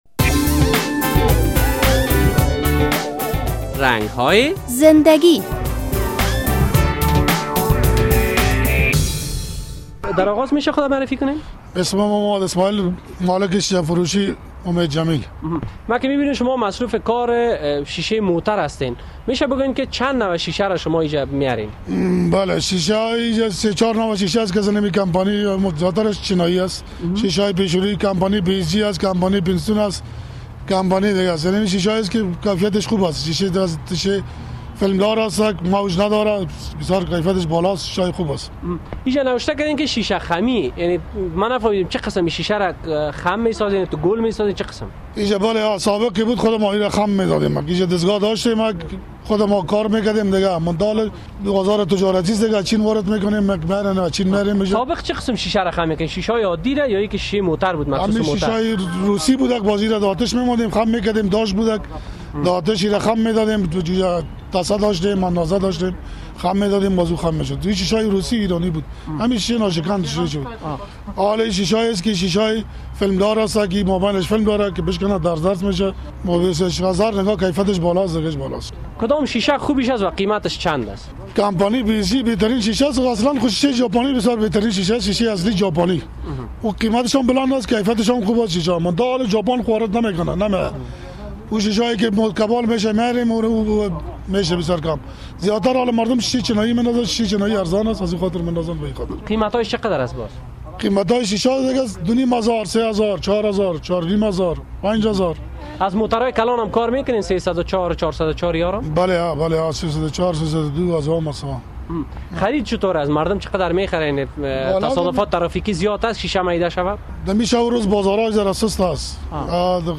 در این برنامهء رنگ های زنده گی خبرنگار رادیو آزادی با یک تن از شیشه فروشان موتر در شهر کابل صحبت شده است و در مورد کار و بار شیشه فروشی موتر از وی پرسیده است.